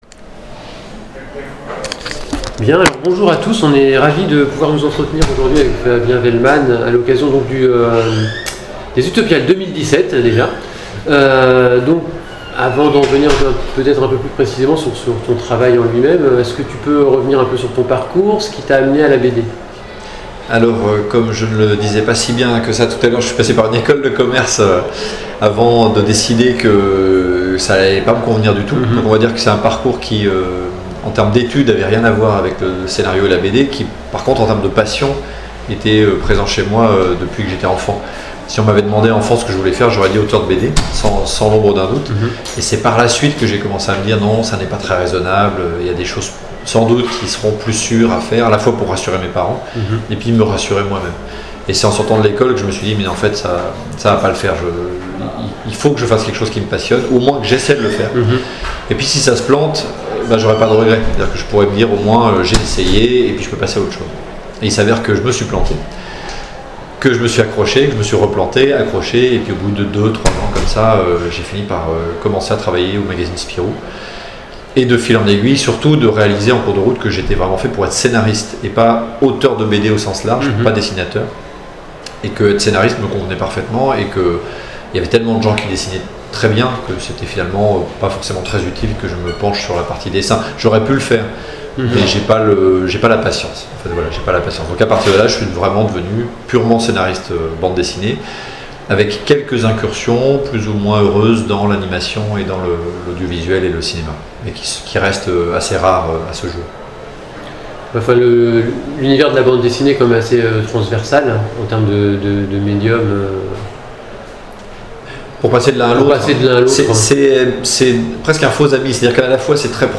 Interview de Fabien Vehlman à l'occasion des Utopiales 2017